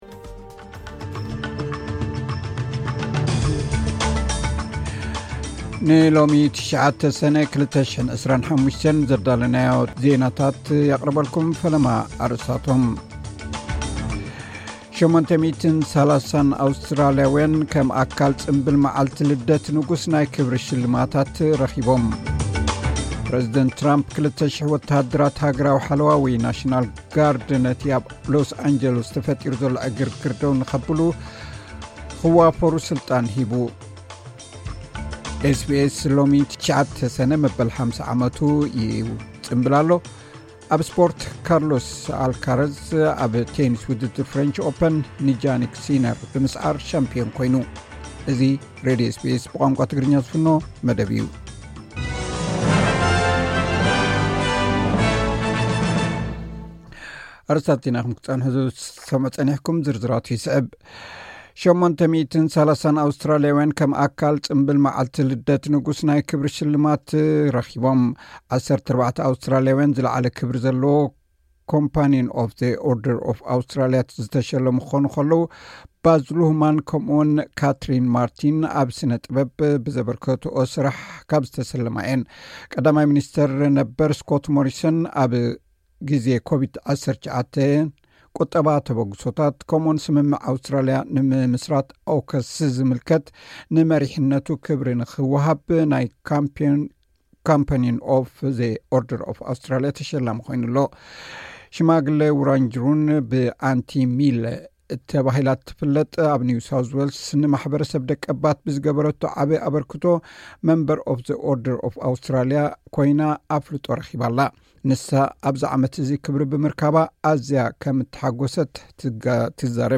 ዕለታዊ ዜና ኤስ ቢ ኤስ ትግርኛ (09 ሰነ 2025)